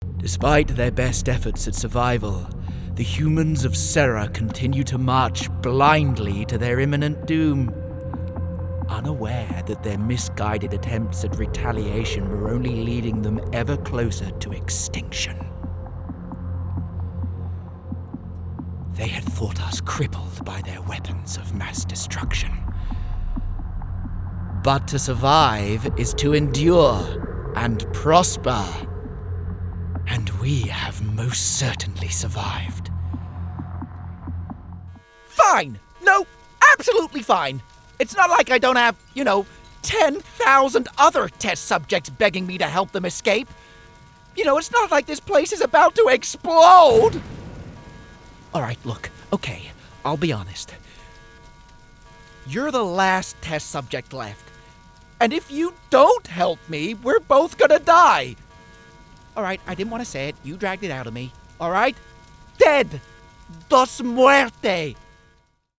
• Native Accent: RP, Welsh
• Home Studio